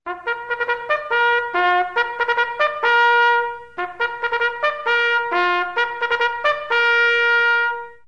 Звуки горна
Сигнал горна перед началом поединка